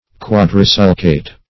Search Result for " quadrisulcate" : The Collaborative International Dictionary of English v.0.48: Quadrisulcate \Quad`ri*sul"cate\, a. [Quadri + sulcate.]
quadrisulcate.mp3